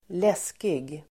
Ladda ner uttalet
läskig adjektiv (vardagligt), horrible , awful Uttal: [²l'es:kig] Böjningar: läskigt, läskiga Synonymer: hemsk, kuslig, otäck, ruskig, ryslig, skräckinjagande, skrämmande Definition: obehaglig, otäck